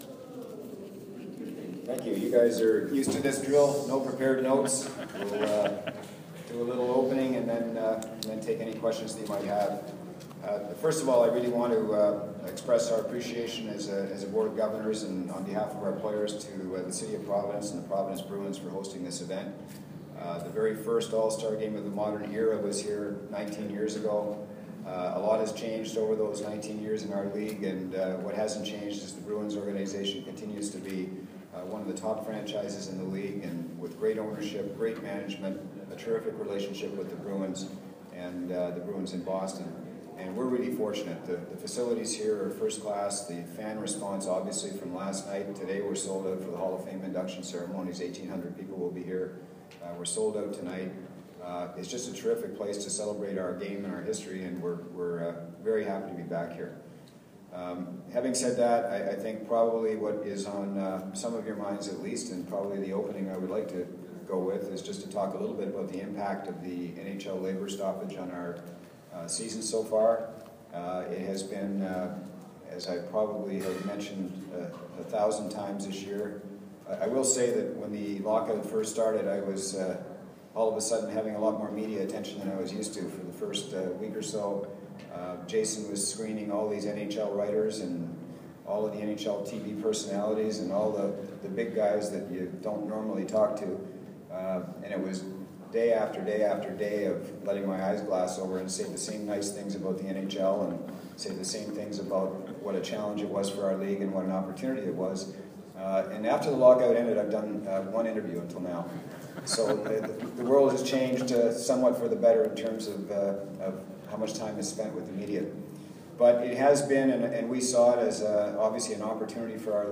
AHL President David Andrews held his yearly State of the League press conference.
Andrews took questions.